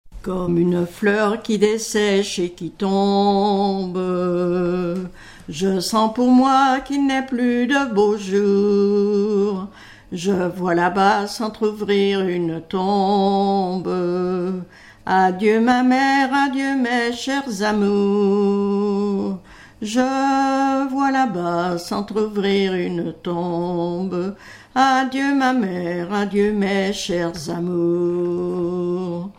Mémoires et Patrimoines vivants - RaddO est une base de données d'archives iconographiques et sonores.
Genre strophique
Pièce musicale inédite